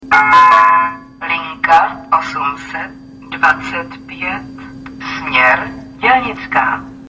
- Hlášení "Linka osm set dvacet pět směr Dělnická" si